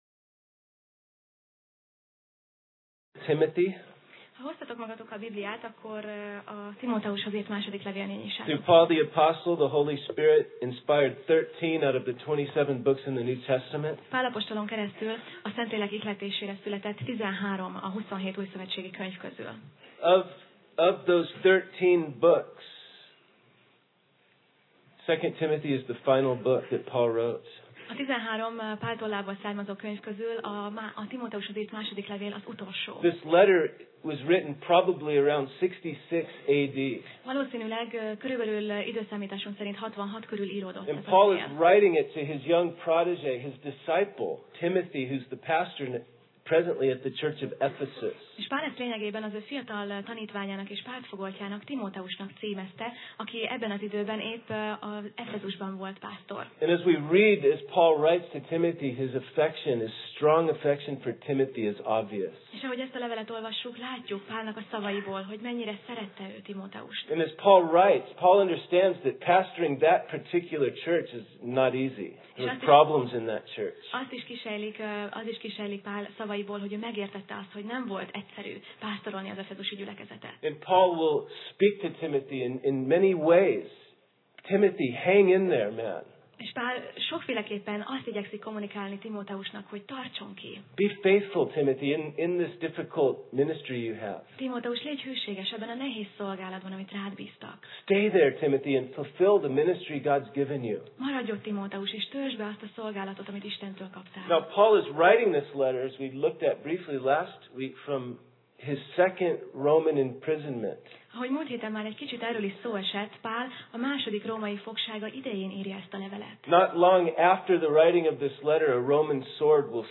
2Timóteus Passage: 2Timóteus (2Timothy) 1:5 Alkalom: Vasárnap Reggel